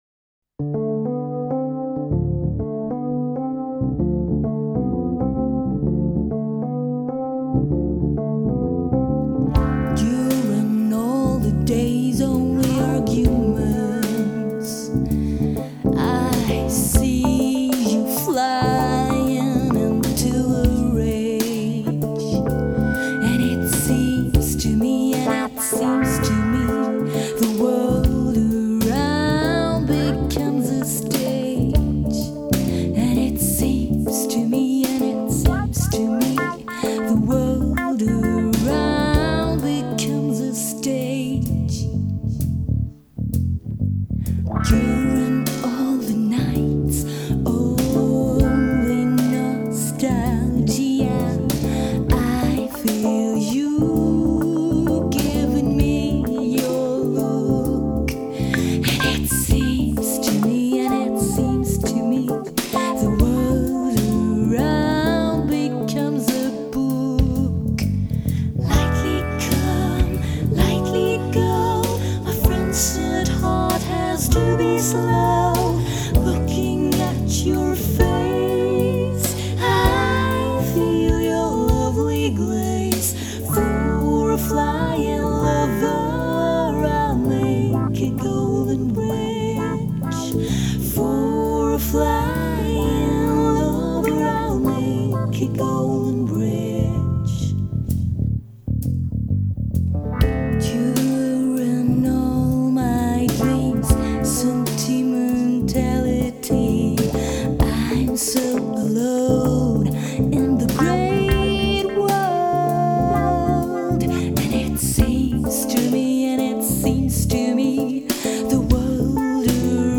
Vocals
Guitar, Piano, Percussion
Bass
Drumset
Backing Vocals
Trombone